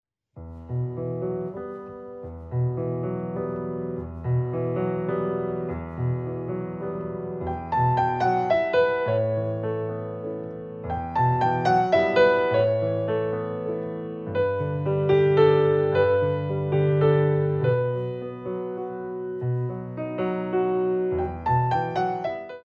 Sicilienne
8x8 - 6/8
Ballet Class Music For First Years of Ballet